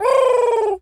pigeon_call_angry_06.wav